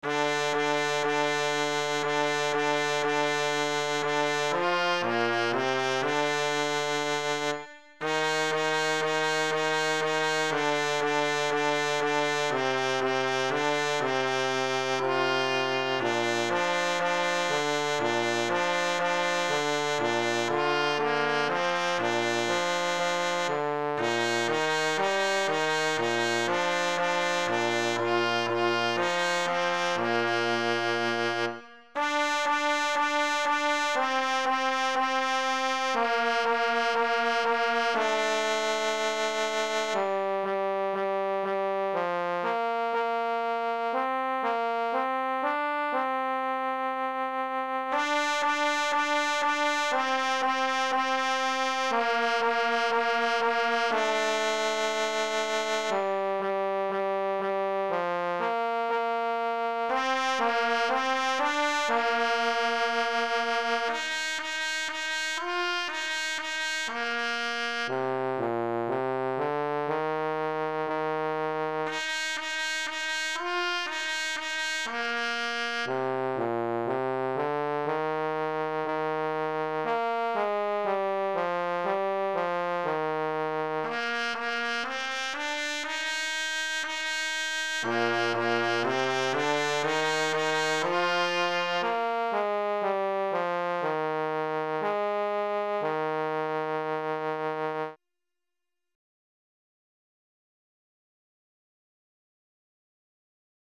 Traditional Melody